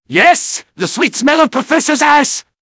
medic_taunts09.mp3